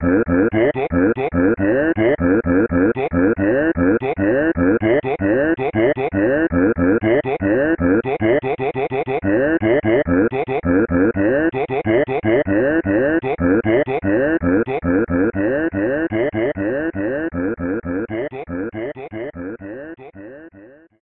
Voice clip from Tetris & Dr. Mario